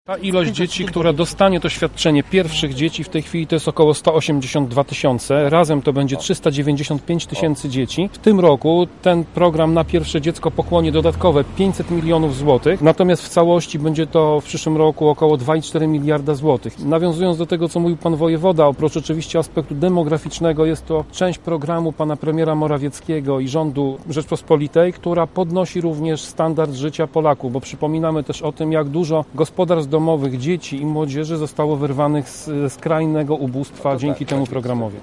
Miejski radny Prawa i Sprawiedliwości Tomasz Pitucha, mówi ile dzieci w naszym województwie będzie mogło skorzystać z programu: